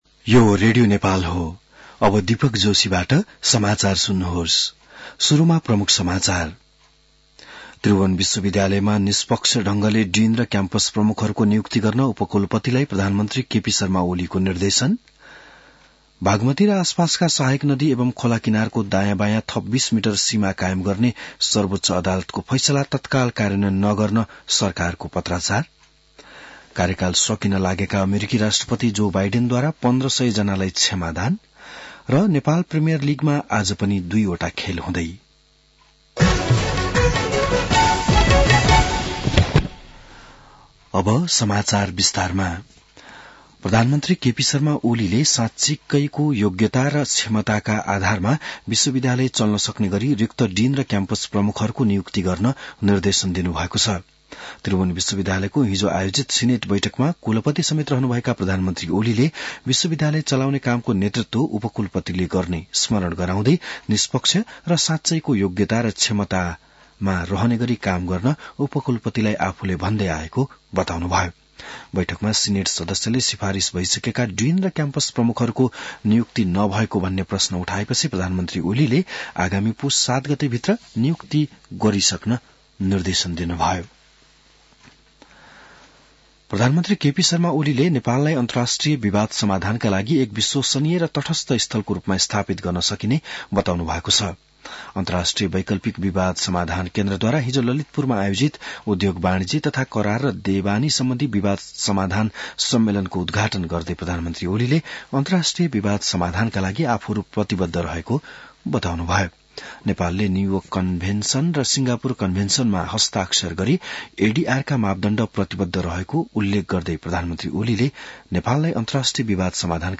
बिहान ९ बजेको नेपाली समाचार : २९ मंसिर , २०८१